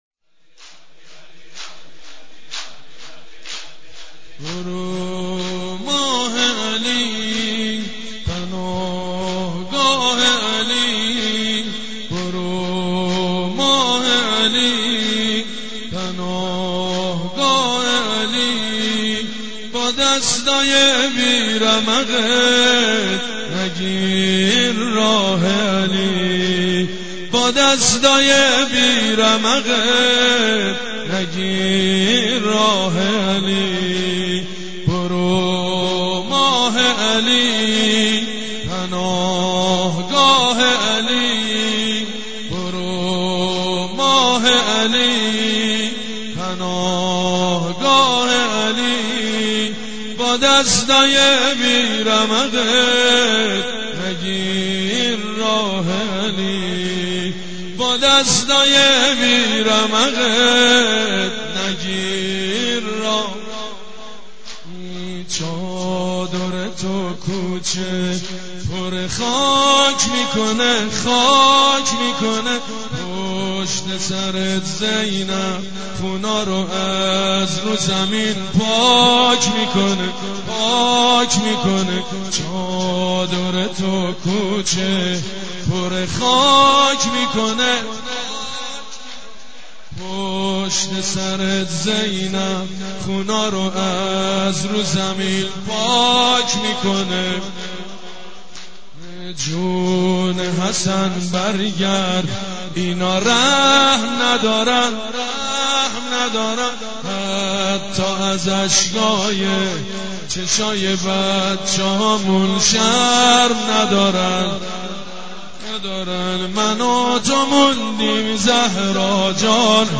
نوحه خوانی و سینه زنی به شهادت حضرت زهرا(س)؛ فاطمیه 89 با صدای مجید بنی فاطمه (9:31)